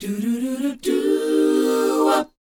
DOWOP F AU.wav